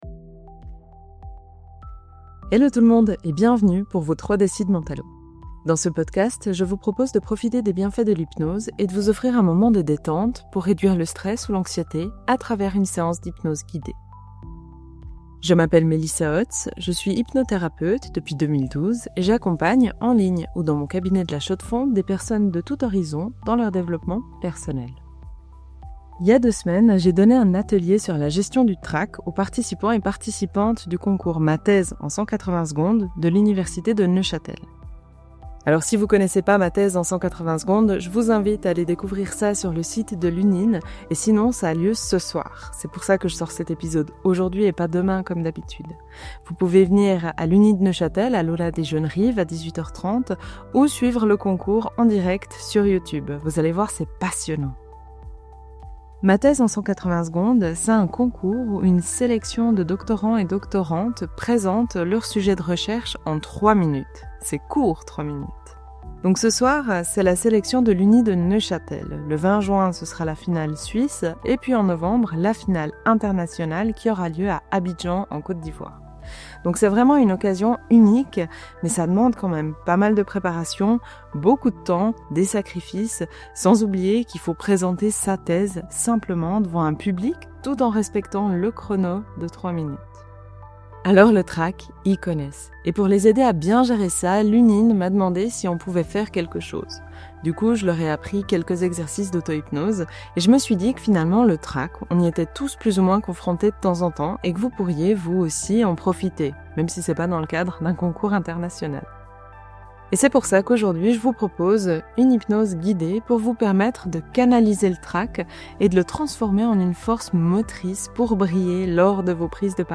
Dans cet épisode de 3 dl de Mental’O, je vous propose une séance d’hypnose guidée pour canaliser le trac et le transformer en une force motrice.